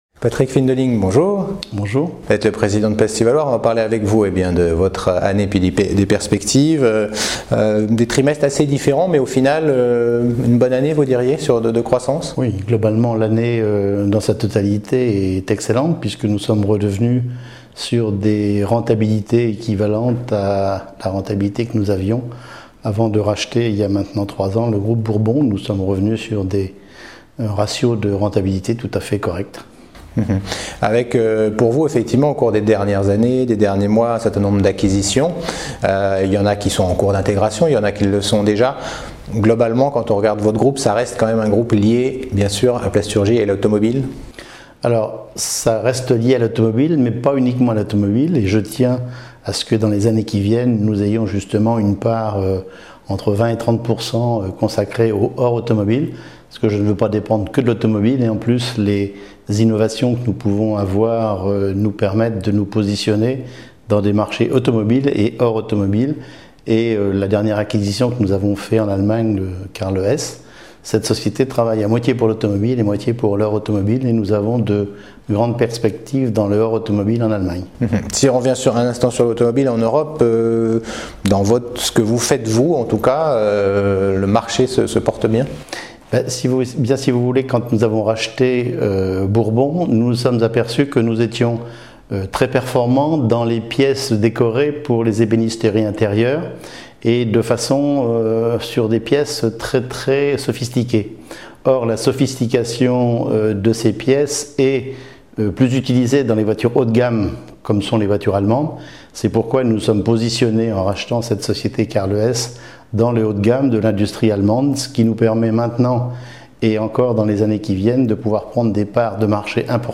Mon invité est